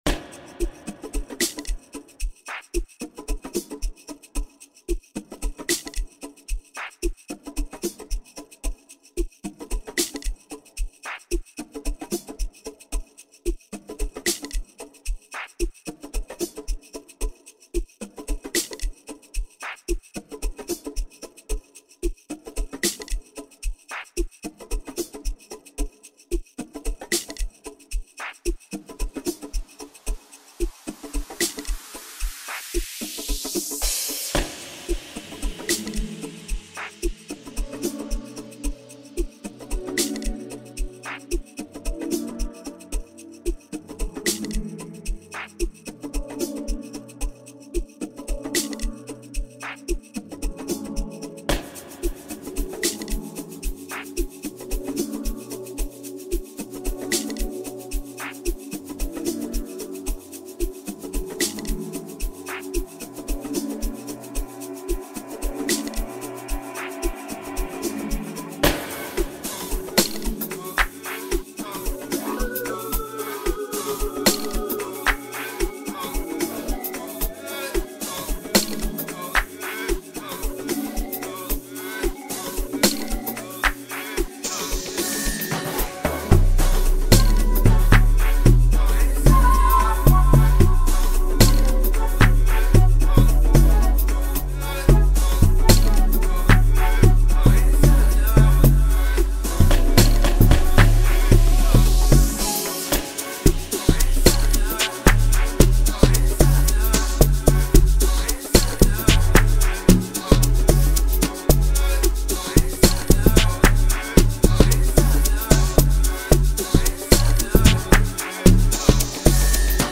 blending traditional Amapiano sounds with a modern twist